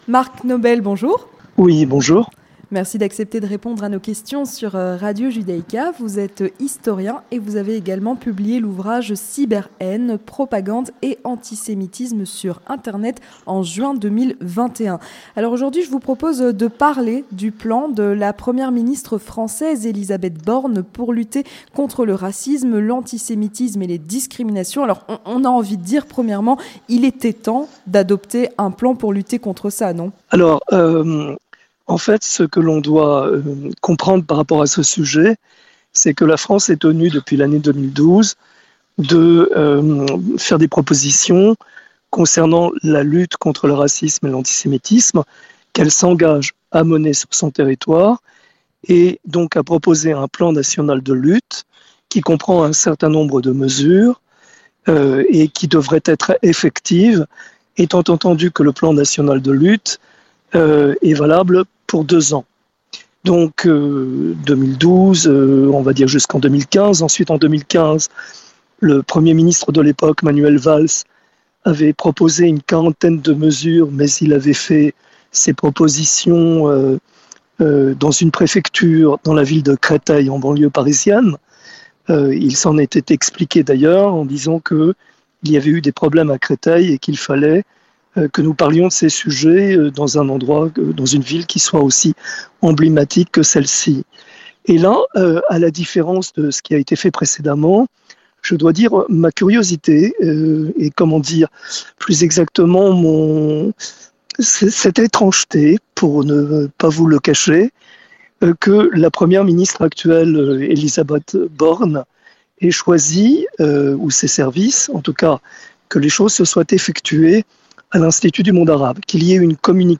Entretien du grand journal (31/01/2023)